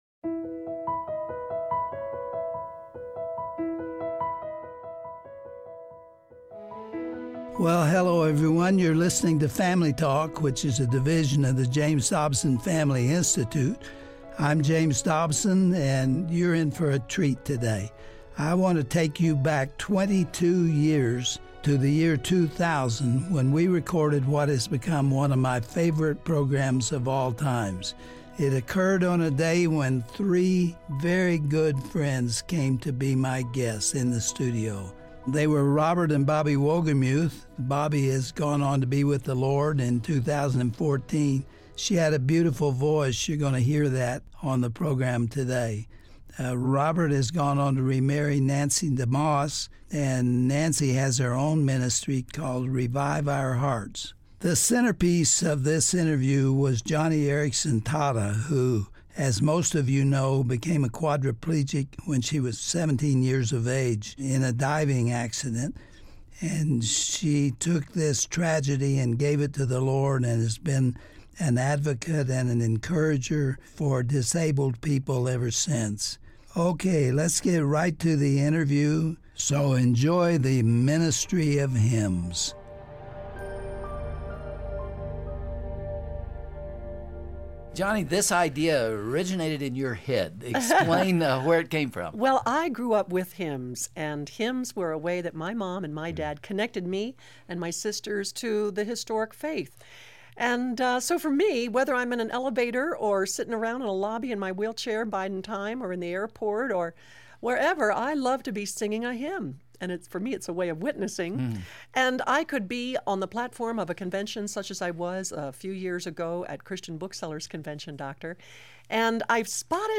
When Joni (pronounced Johnny) Eareckson Tada broke her neck in 1967 and became a quadriplegic, she languished in her hospital bed for a year. On today’s edition of Family Talk, Joni shares that during that time, she was encouraged when her friend visited her and sang the hymn “Man of Sorrows.”